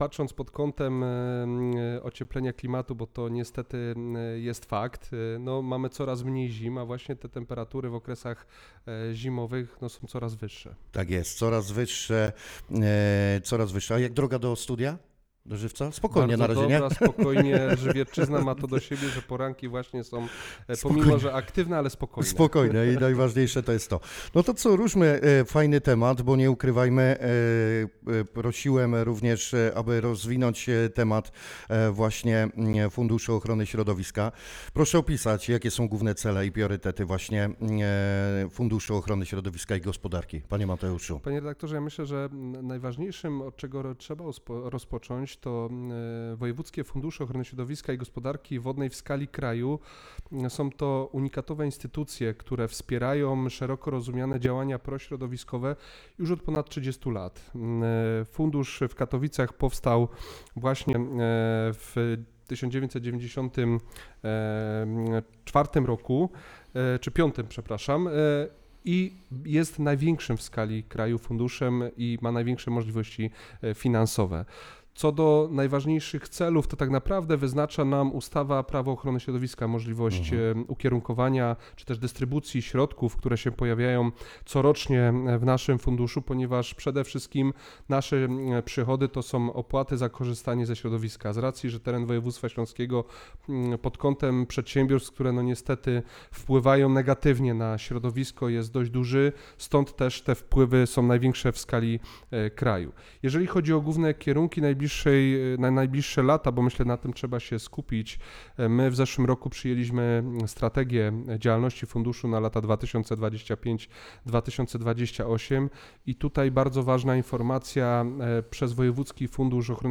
Dziś w naszym studiu gościliśmy Mateusza Pindla, Prezesa Zarządu Wojewódzkiego Funduszu Ochrony Środowiska i Gospodarki Wodnej w Katowicach.
Załączniki Wywiad z Mateuszem Pindel